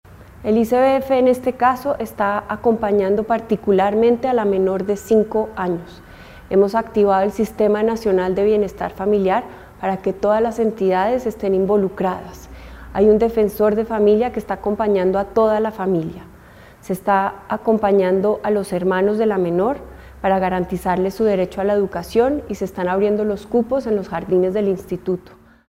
La Directora General del ICBF señala desde el Sistema Nacional de Bienestar Familiar se activó la ruta de atención por parte de todas las entidades y un Defensor de Familia acompaña a la familia.